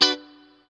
CHORD 2   AE.wav